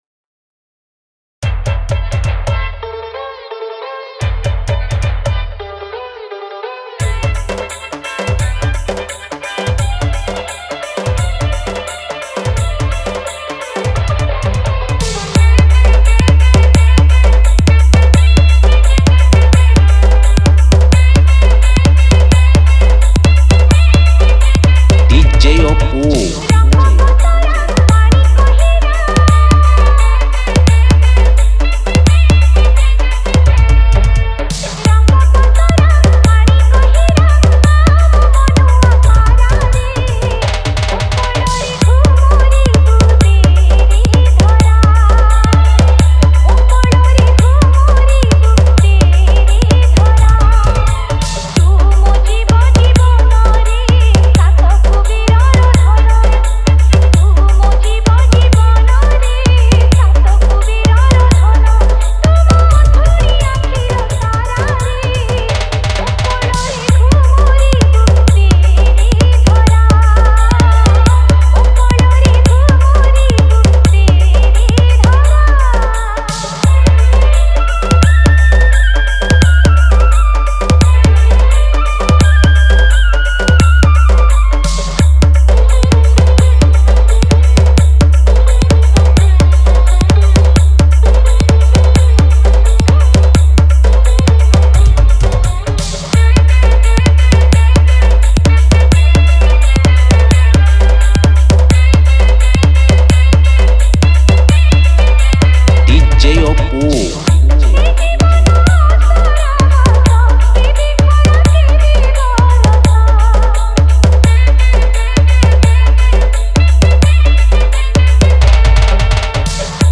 Odia Bhakti Tapori Dance Mix